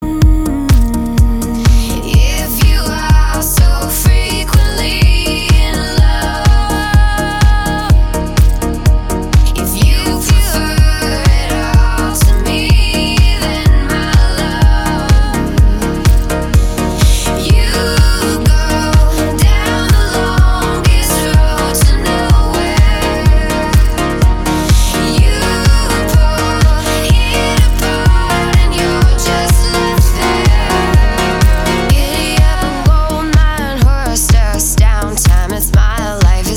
Genre: Progressive House (with Trance undertones)
Tranciness: Medium
Progressiveness: High
Danciness/Intensity: Medium